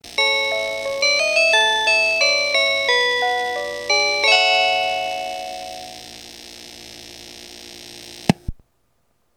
●キハ４０系
「リゾートしらかみ青池編成」に使用されてるキハ４０で、４曲のクラシックチャイムが流れ、チャイムボタン順に掲載してます。
＊音が悪いですがご了承ください。
kiha40-chime2.mp3